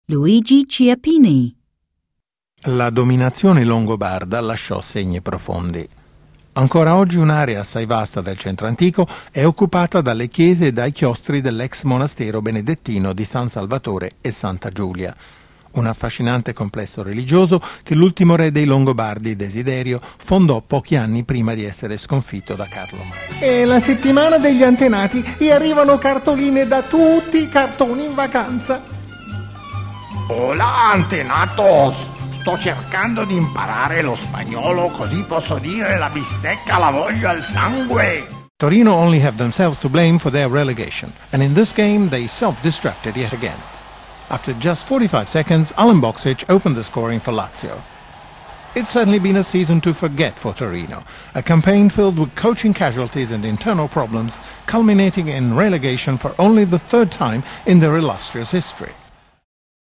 Italian voiceover artist